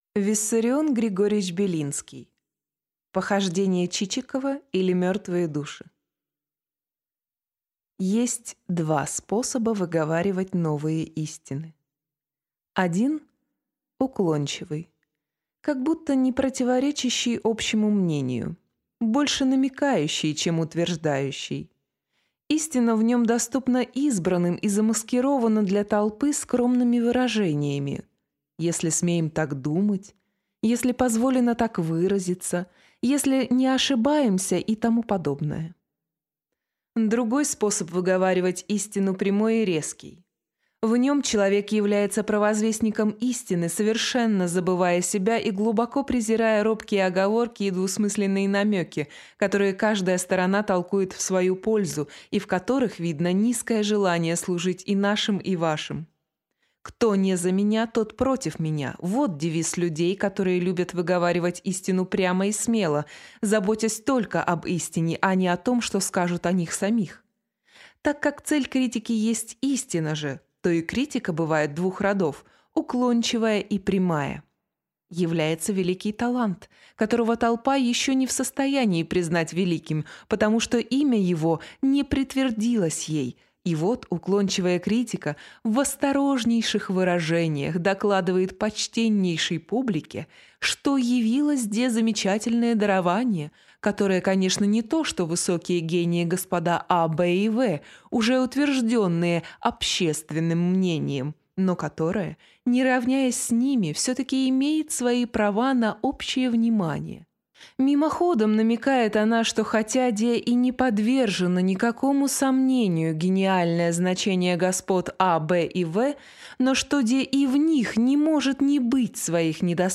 Аудиокнига Похождения Чичикова, или Мертвые души | Библиотека аудиокниг